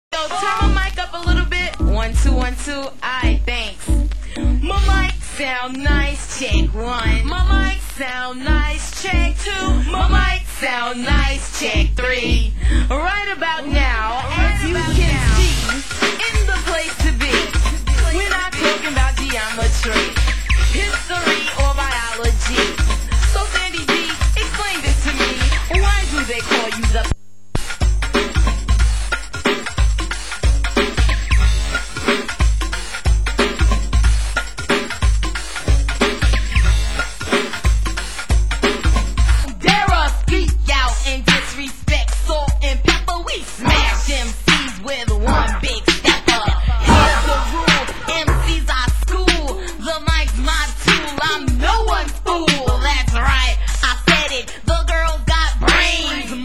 Genre: Hip Hop